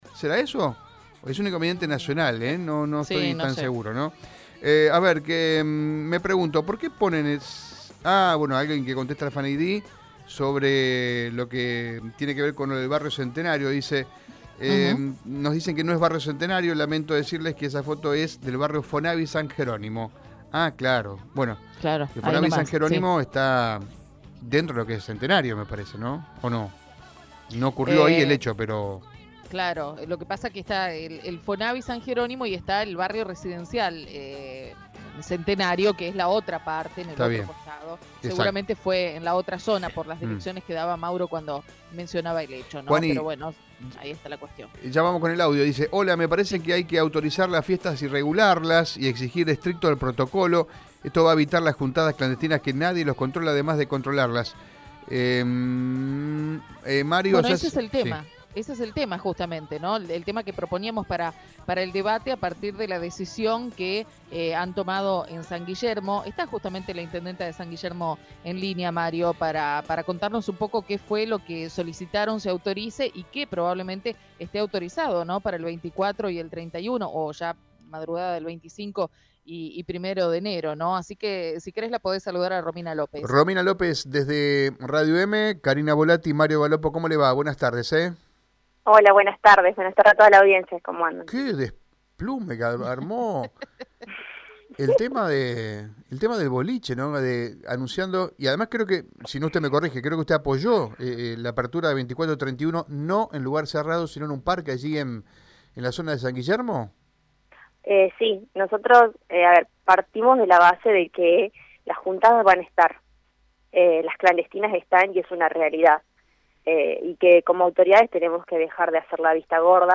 La intendente de San Guillermo, Romina López, habló en Radio EME sobre la habilitación y regulación de espacios para los festejos del 24 y 31 de diciembre.